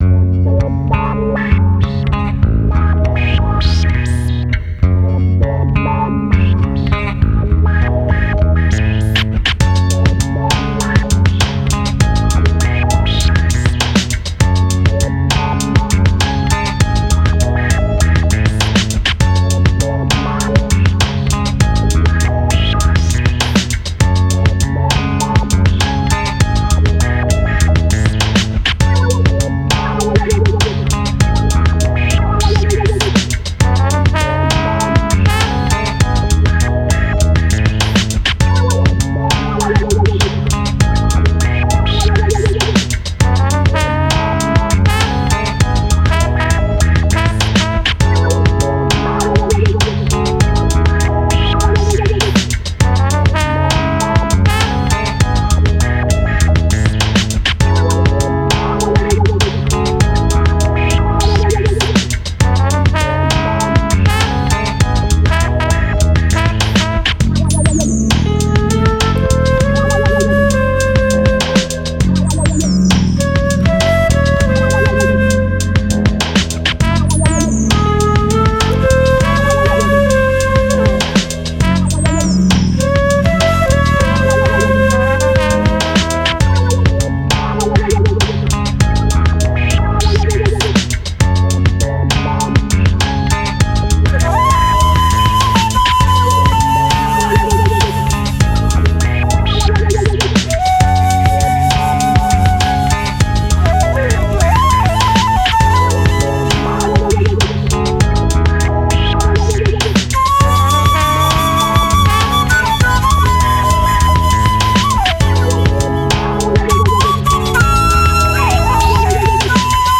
sax and flute
signature bass grooves